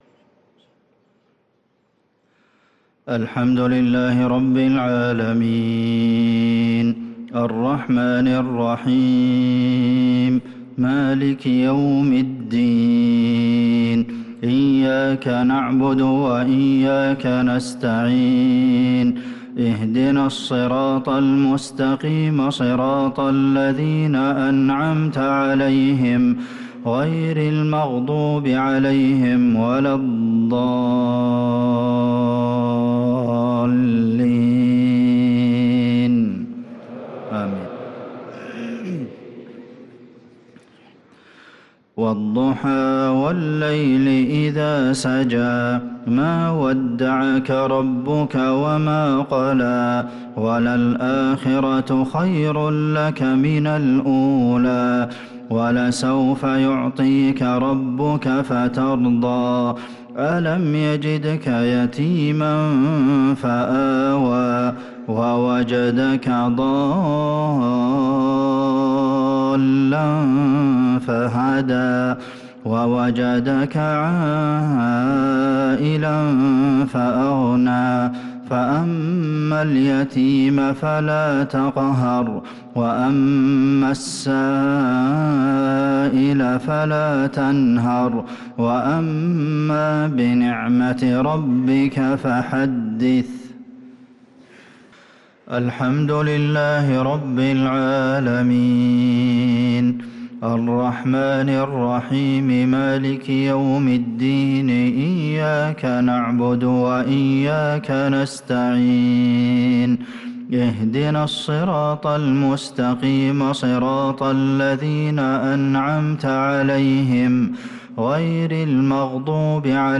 صلاة المغرب للقارئ عبدالمحسن القاسم 9 شعبان 1445 هـ